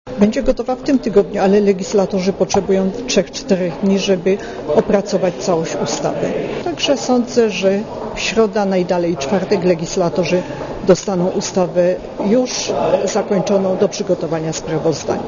Ustawa zdrowotna będzie gotowa na czas - zapewniają posłowie. Szefowa sejmowej komisji zdrowia Barbara Błońska-Fajfrowska mówi, że prace nad nowymi przepisami posłowie skończą jeszcze w tym tygodniu, a w przyszłym ustawą zajmie się Sejm.